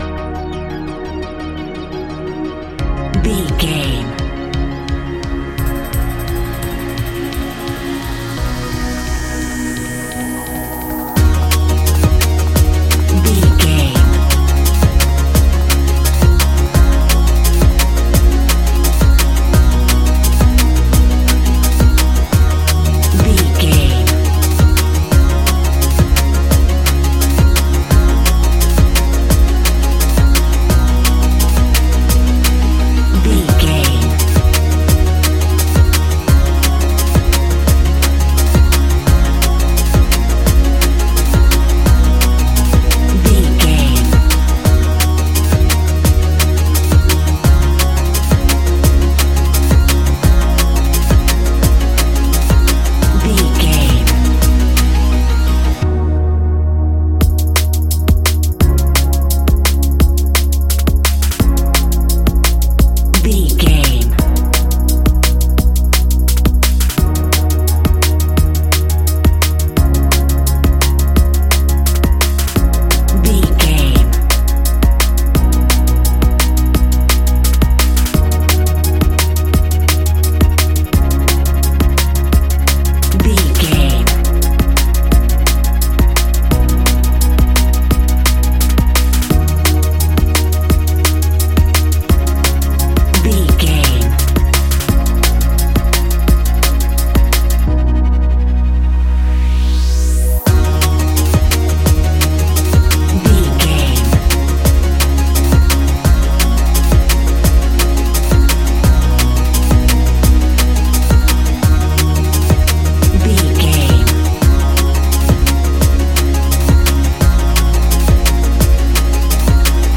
Ionian/Major
electronic
techno
trance
synths
synthwave
instrumentals